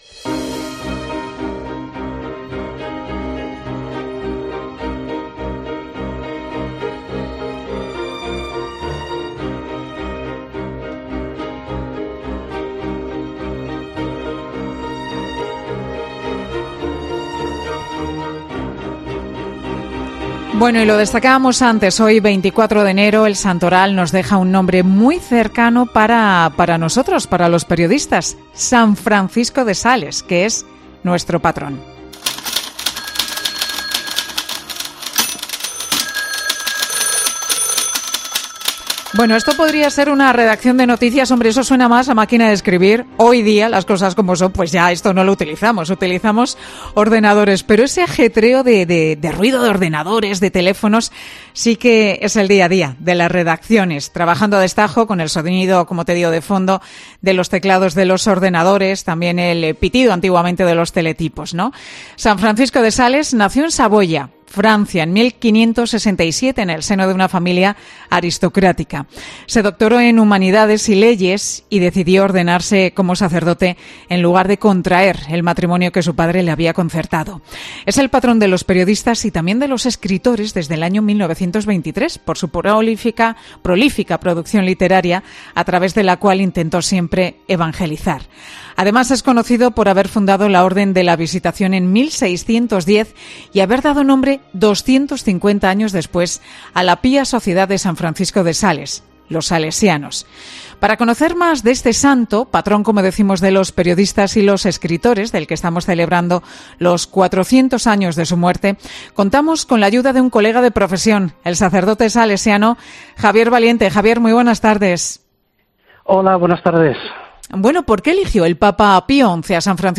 El periodista y salesiano ha estado en 'Mediodía COPE' con Pilar García Muñiz por el día del patrón de los periodistas y escritores